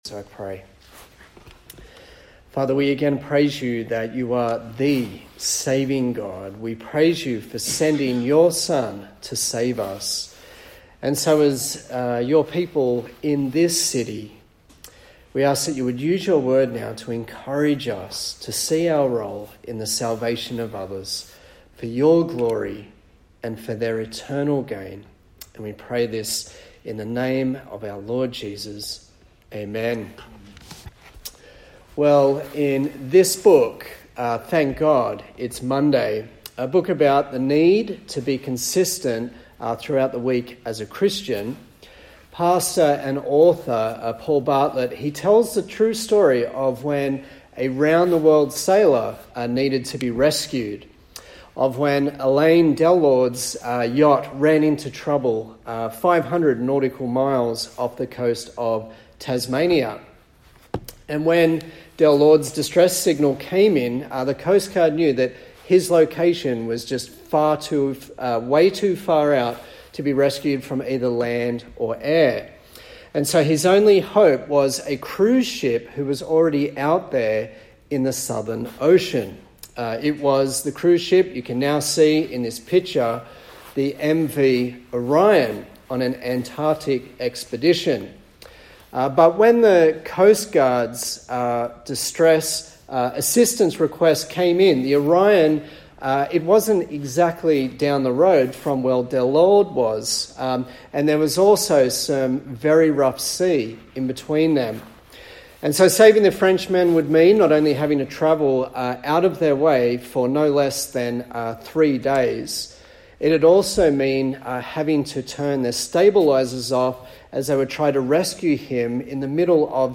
Acts Passage: Acts 13:1-12 Service Type: Sunday Morning